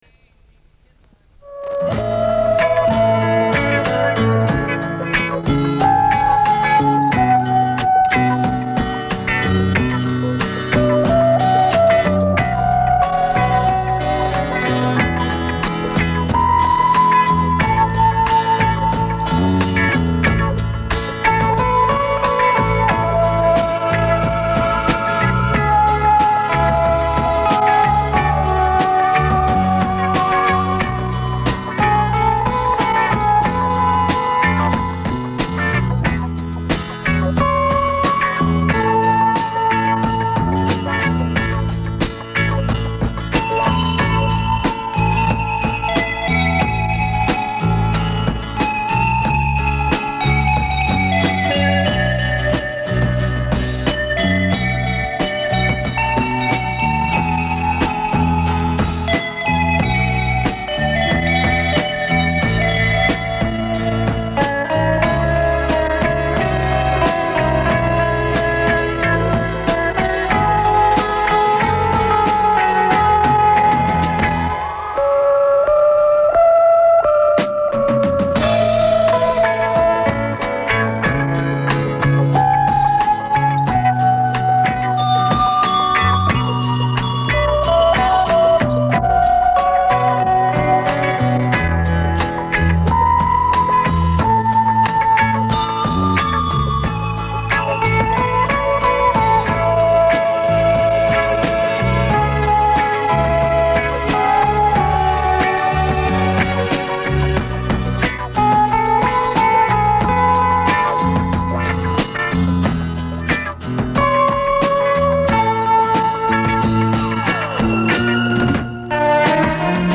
Выкладываю моновариант